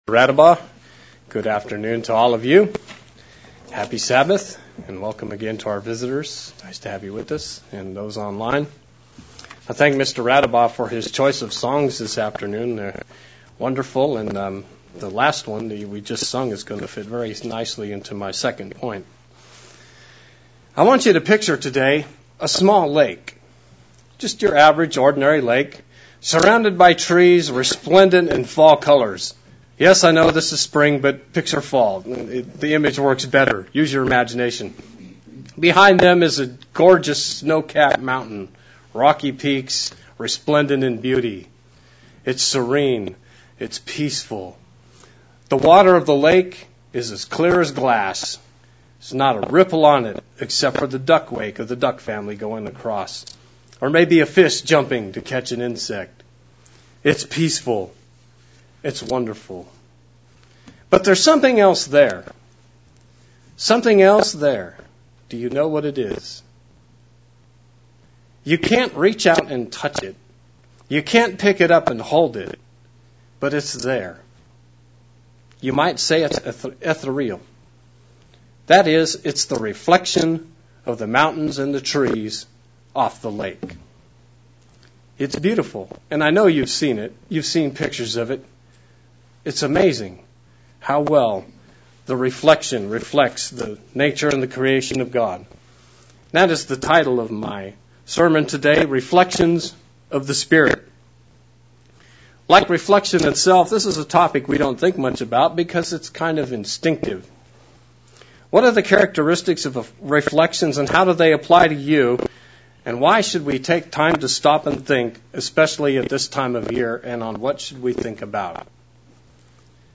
Pre Passover sermon on things we should reflect on before Passover. Consider a lake and how it reflects the imagery around it.
Audio sound is okay, but has some issues.